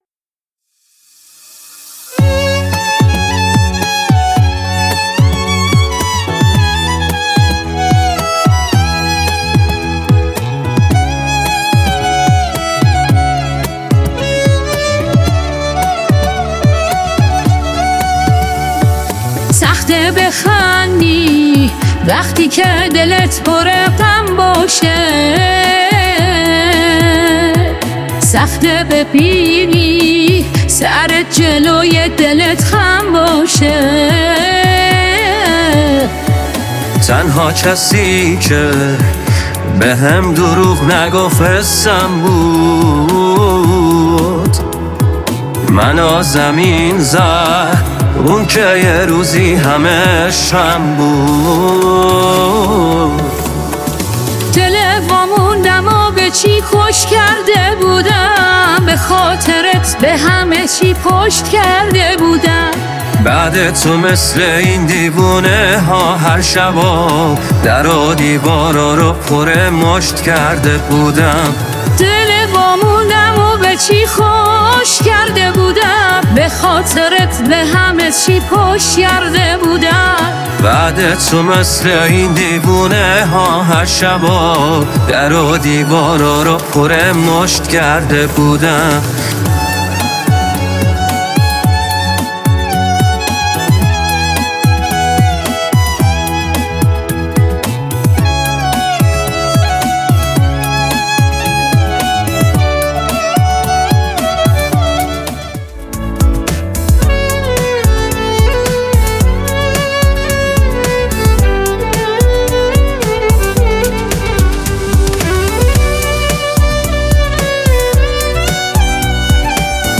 اجرای دونفره